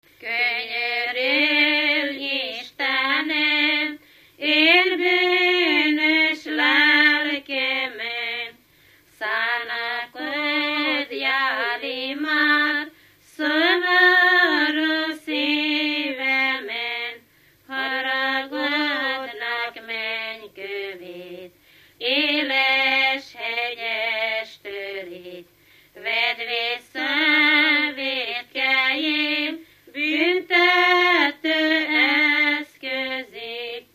Dunántúl - Verőce vm. - Lacháza
Stílus: 3. Pszalmodizáló stílusú dallamok
Kadencia: 1 (1) V 1